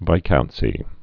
(vīkountsē)